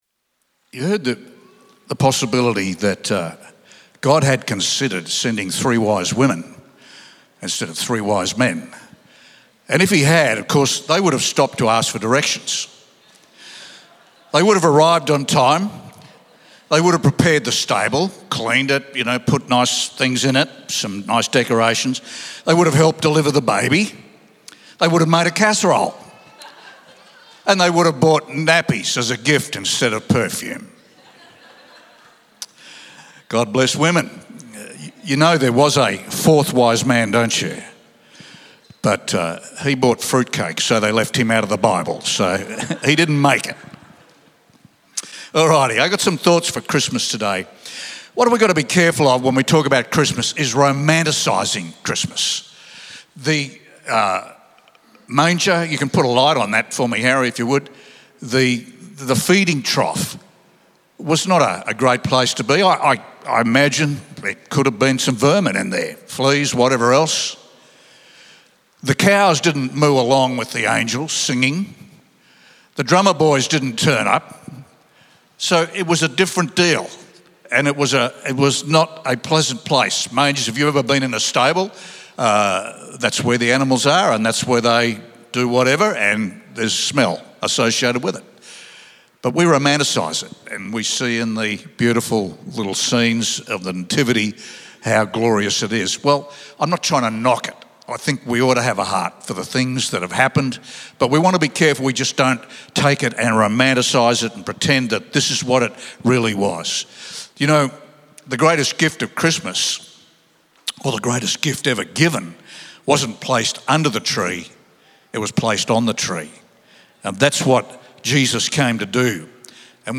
Weekly Sermons